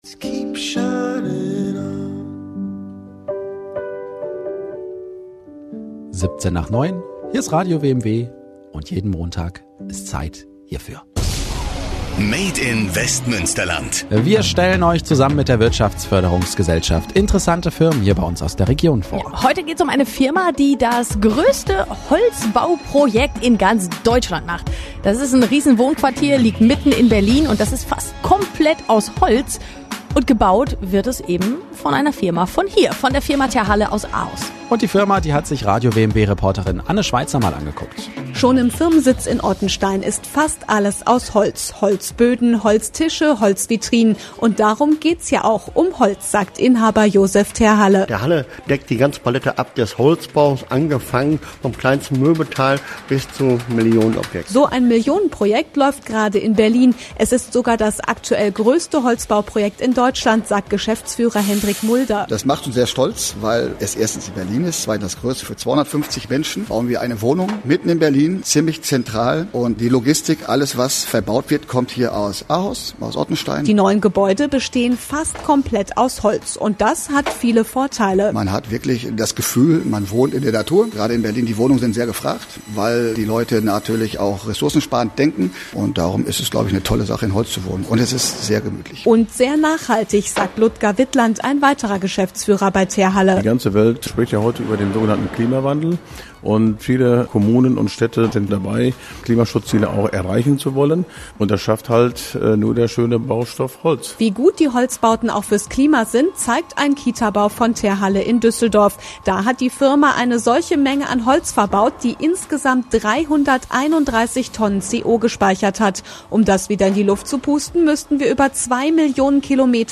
Radio WMW berichtet über Terhalle: „Made im Westmünsterland“